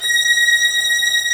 STR VIOLI0HL.wav